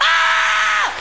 Ahhhhhhhhh Sound Effect
ahhhhhhhhh.mp3